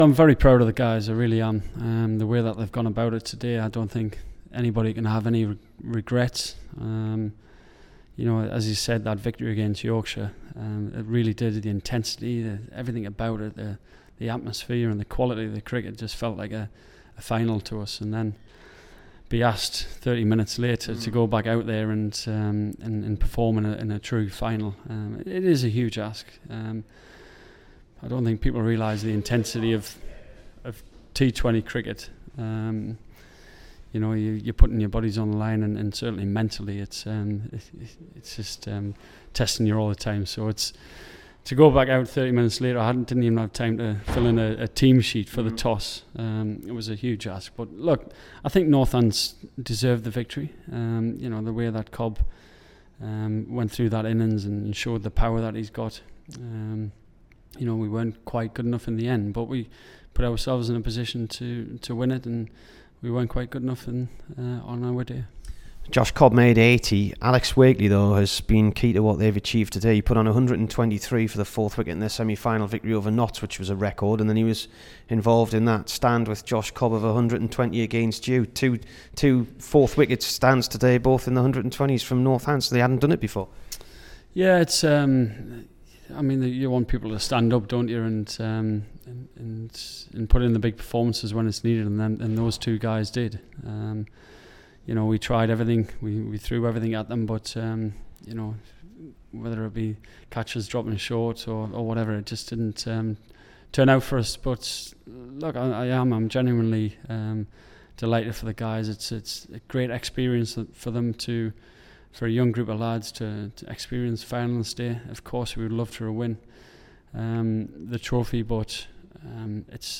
HERE'S THE DURHAM SKIPPER AFTER THE T20 FINAL DEFEAT TO NORTHANTS.